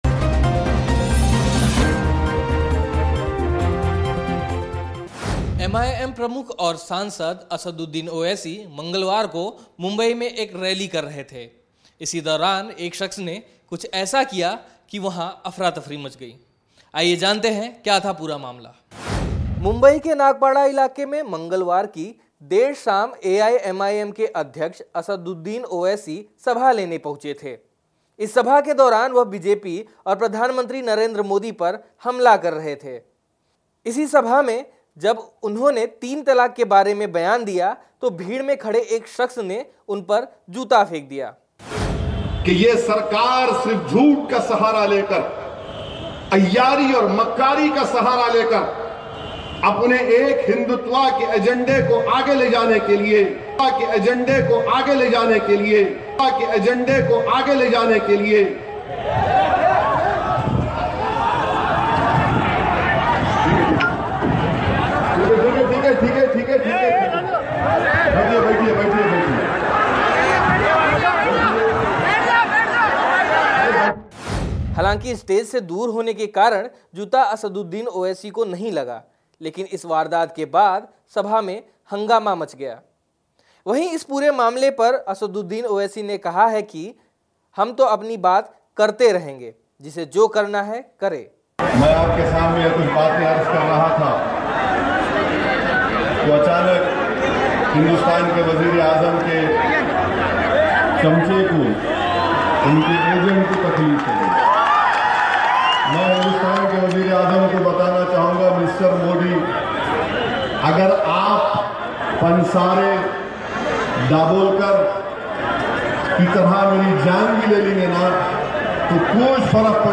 News Report / मुंबई के नागपाड़ा में रैली के दौरान असदुद्दीन ओवैसी पर फेंका गया जूता